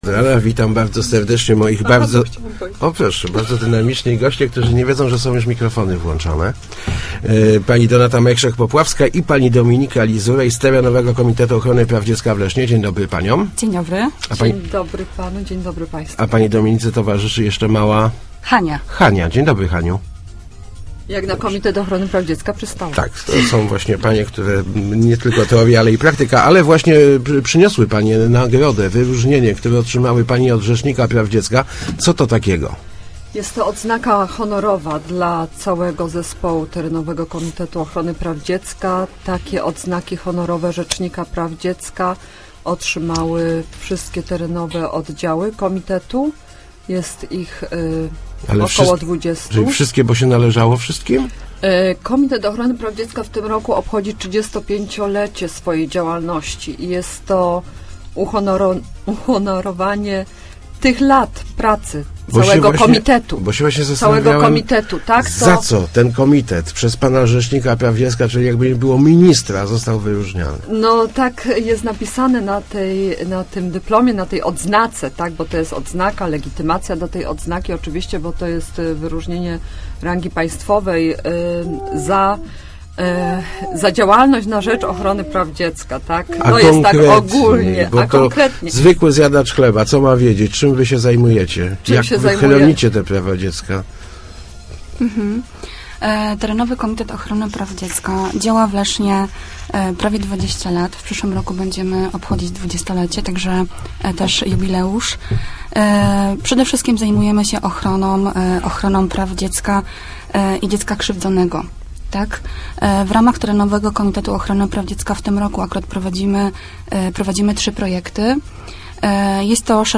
mówiły w Rozmowach Elki